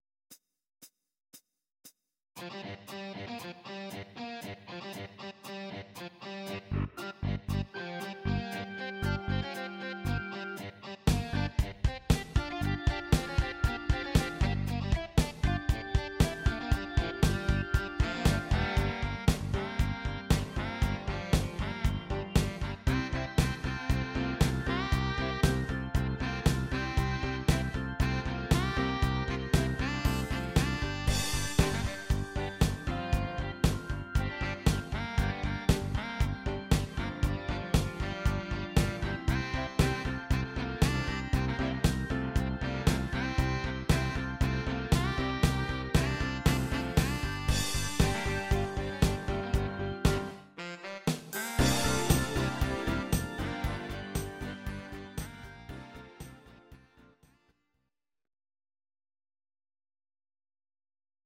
These are MP3 versions of our MIDI file catalogue.
Please note: no vocals and no karaoke included.
cover